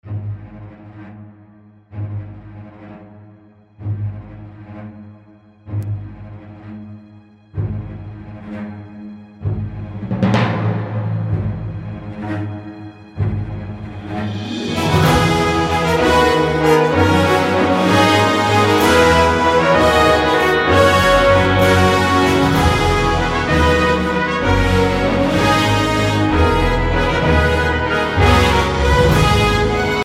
i just slowed down the piece and made the repetitions even faster
this way the problem doesn't come to attention that much [:)]
repetitions_fast96bpm.mp3